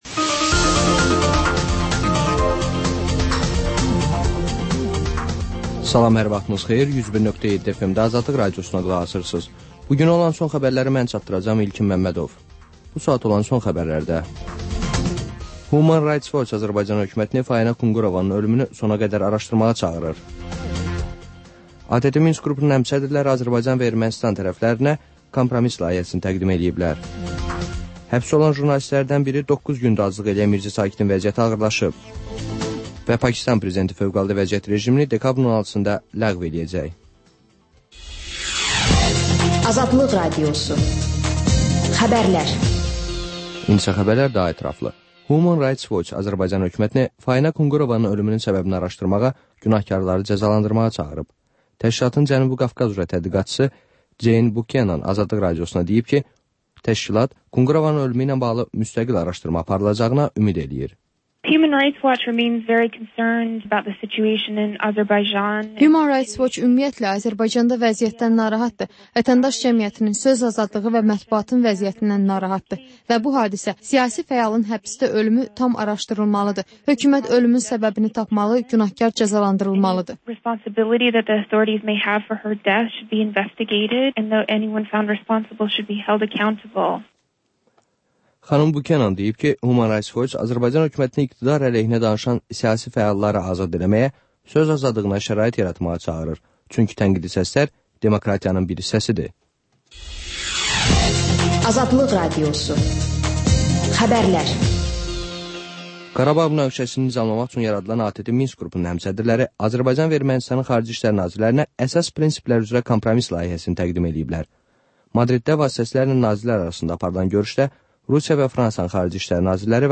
Xəbərlər, müsahibələr, hadisələrin müzakirəsi, təhlillər, sonda QAFQAZ QOVŞAĞI rubrikası: «Azadlıq» Radiosunun Azərbaycan, Ermənistan və Gürcüstan redaksiyalarının müştərək layihəsi